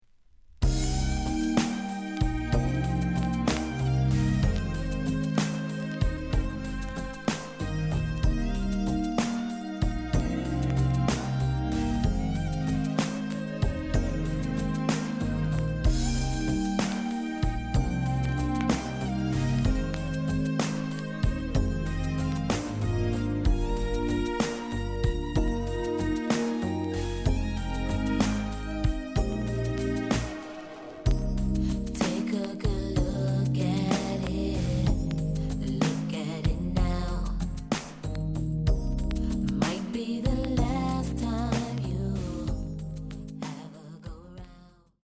The audio sample is recorded from the actual item.
●Genre: Hip Hop / R&B
Looks good, but slight noise on both sides.)